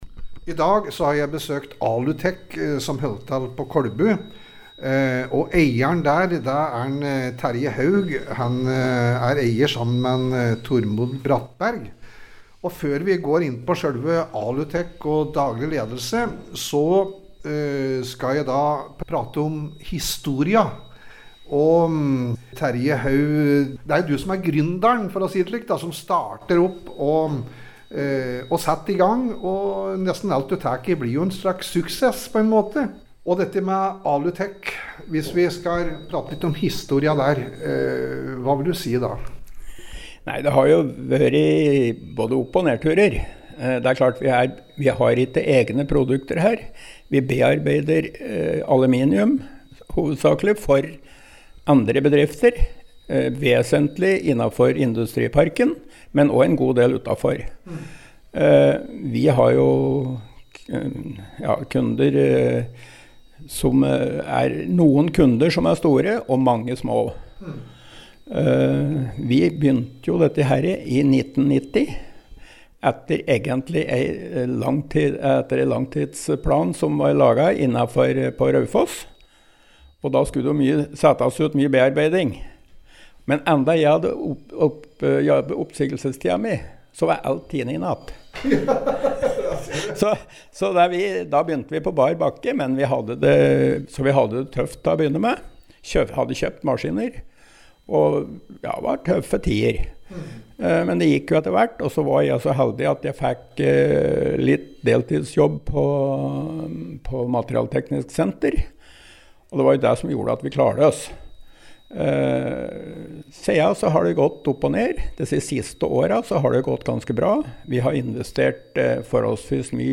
Vi har besøkt bedriften og her hører vi først en prat